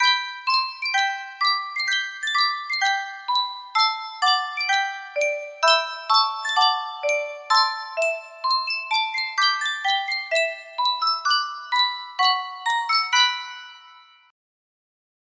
Superstar Music Box - Toreador Carmen T4 music box melody
Full range 60